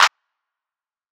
Clap 1 [ metro ].wav